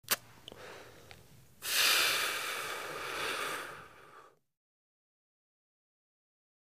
Cigarette; Heavy Inhale And Exhale